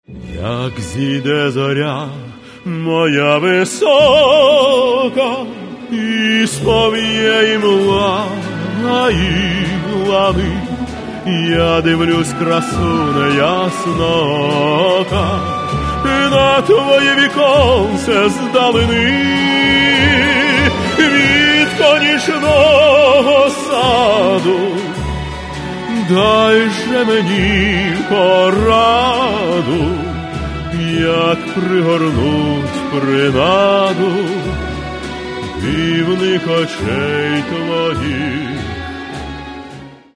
Каталог -> Эстрада -> Певцы
Они посвящены – романсам.